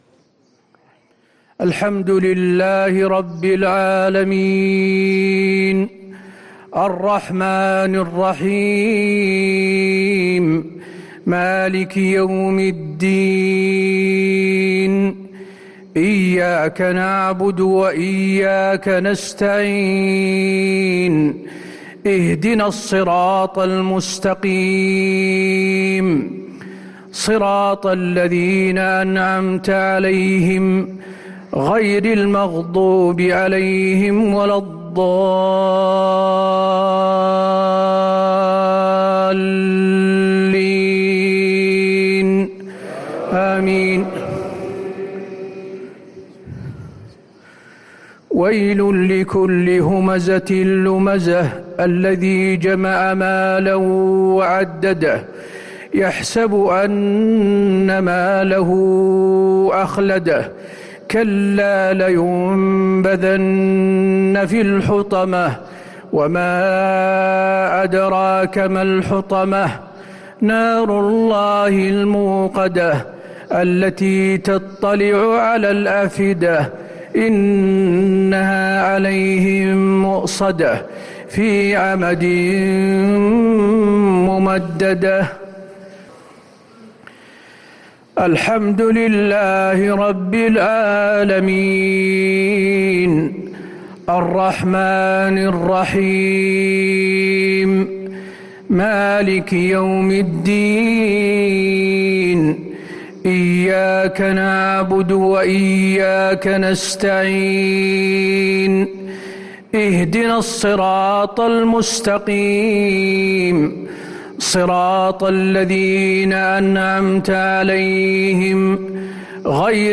صلاة الجمعة 6 صفر 1444هـ سورتي الهمزة و الإخلاص | Jumu'ah prayer from Surah Al-Humaza and Al-Ikhlaas 2-9-2022 > 1444 🕌 > الفروض - تلاوات الحرمين